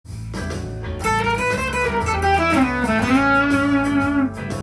③のフレーズは、クロマチックスケールで始まり
クロマチックスケールで気持ち悪い感じもしますが
安定感を出しているのでクロマチックスケールの不安定感和らげている感じです。